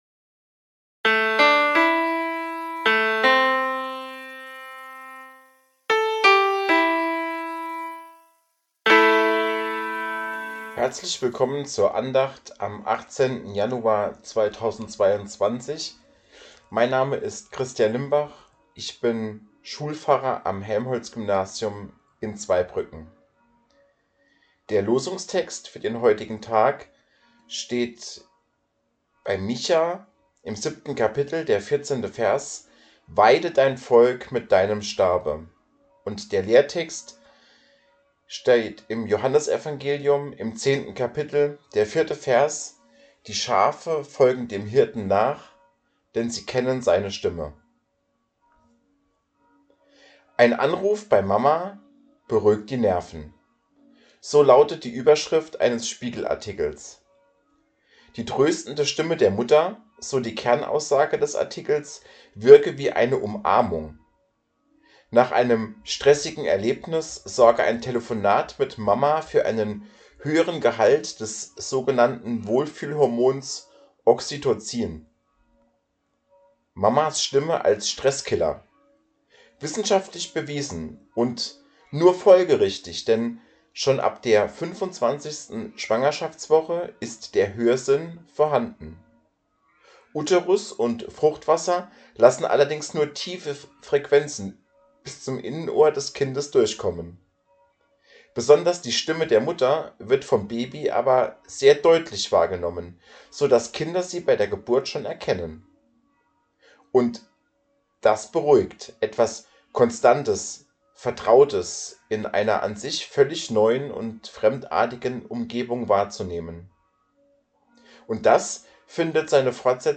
Losungsandacht für Dienstag, 18.01.2022